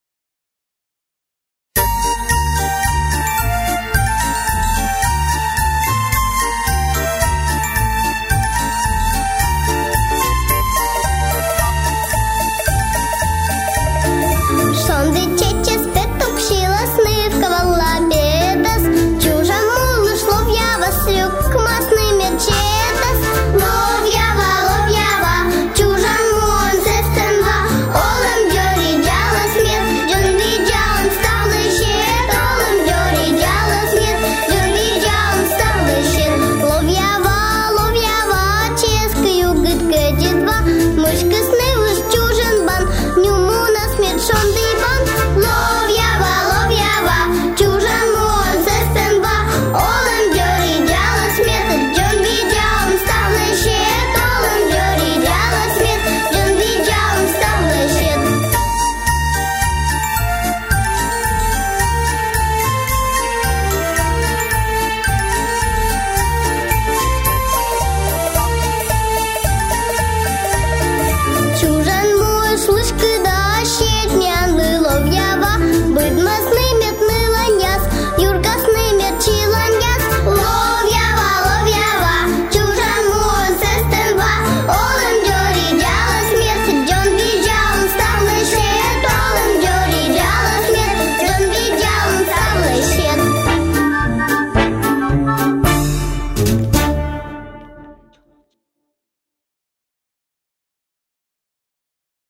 Сьыланкыв